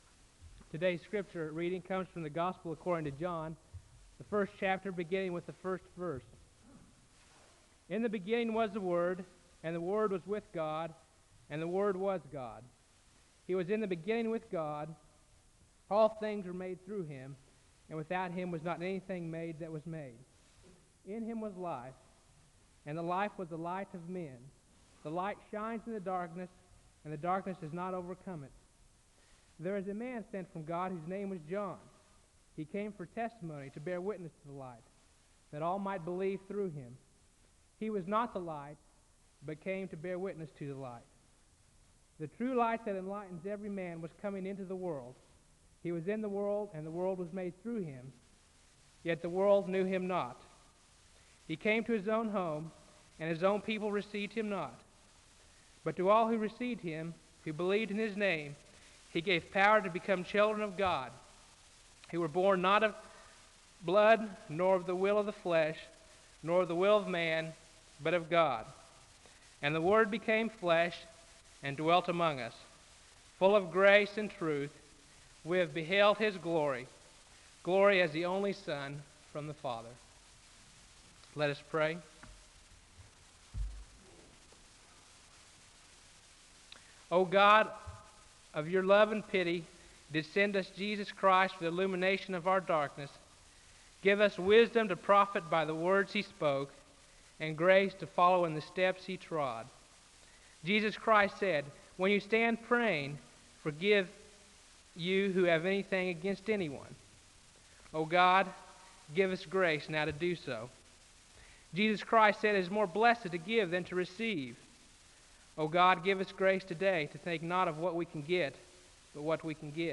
The service begins with the speaker reading from John 1, and he gives a word of prayer (00:00-03:16).
The choir sings a song of worship (03:17-06:12).
Church work with the poor Incarnation